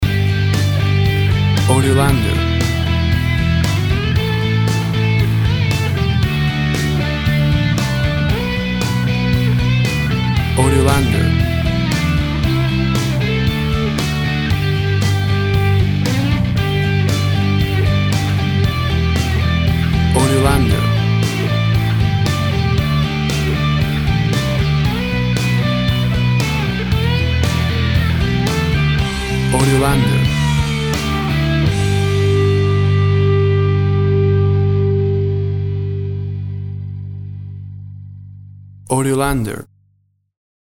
A big and powerful rocking version
Full of happy joyful festive sounds and holiday feeling!.
Tempo (BPM) 100